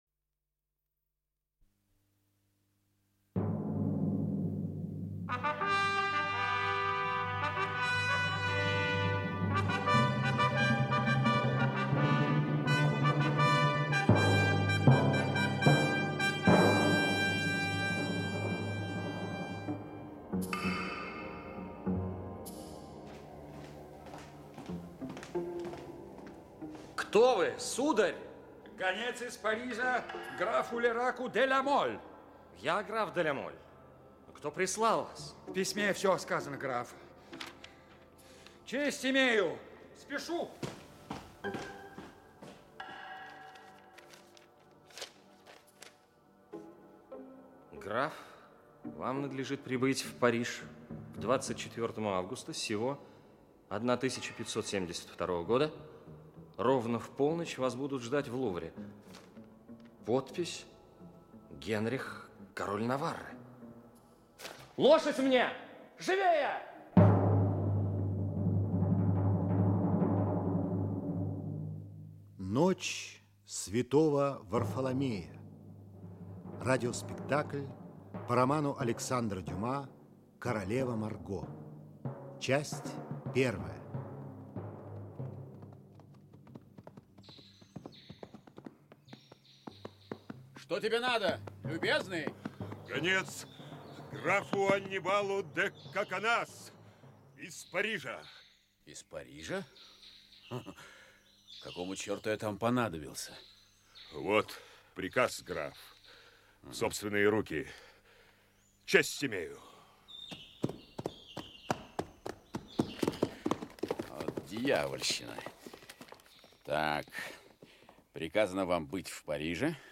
Радиоспектакль по мотивам романа«Безземельная королева и безмужняя жена», королева Марго, по политическим соображениям выданная замуж за Генриха Наваррского, обращает свою женственность в беспощадное...